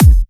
VFH1 128BPM Moonpatrol Kick.wav